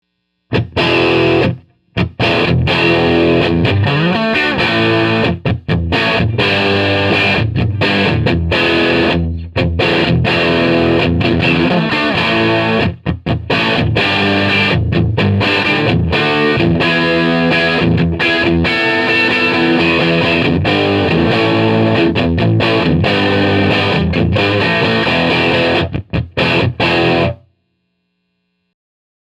It's about 127 decibels in the room, and I didn't want the cops to show up, so I kept it short and didn't get a chance to experiment with mic placement.
There's very little hiss/hum - a very good signal to noise ratio, I mean.
the track is dry.